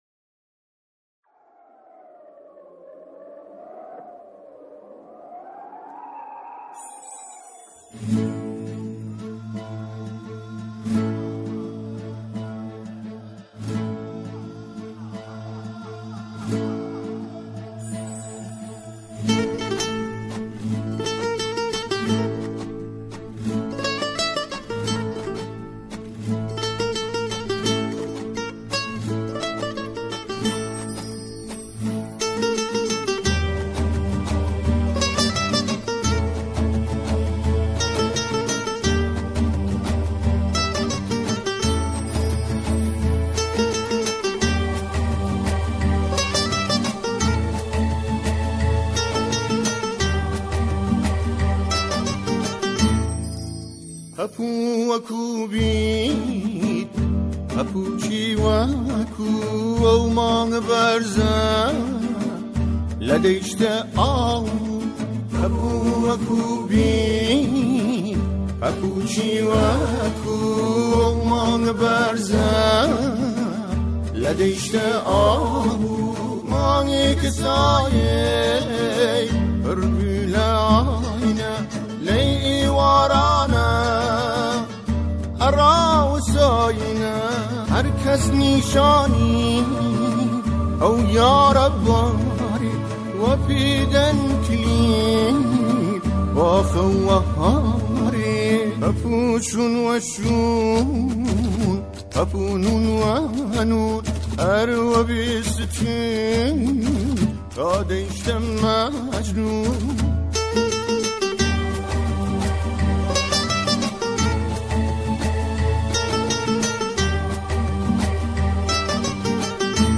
در تنظیم این آهنگ از سازهای غربی مثل گیتار، استفاده شده است.